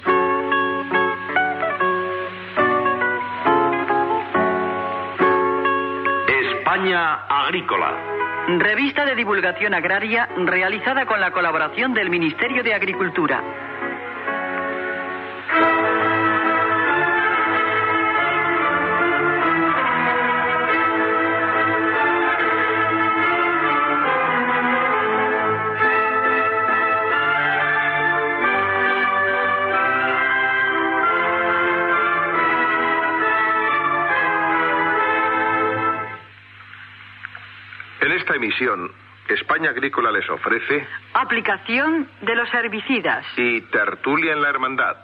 Careta i sumari del programa
Fragment extret del programa "La radio con botas", emès per Radio 5, l'any 1991